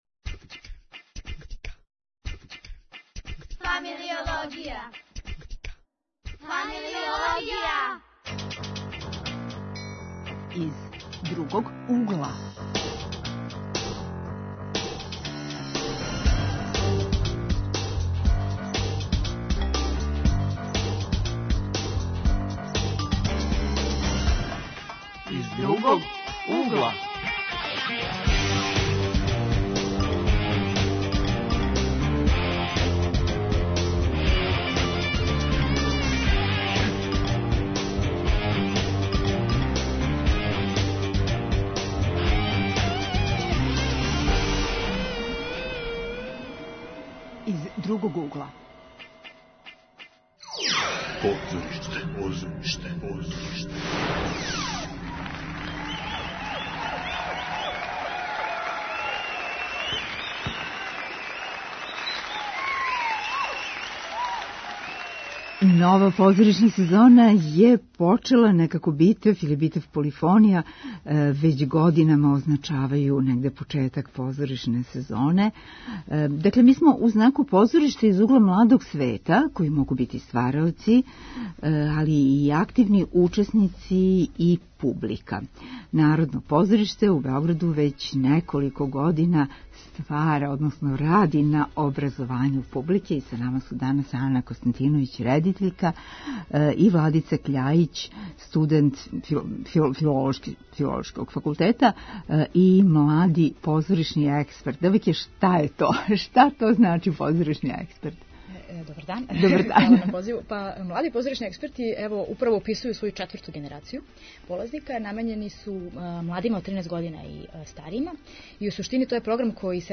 Гости у студију биће учесници програма Млади позоришни, експерти.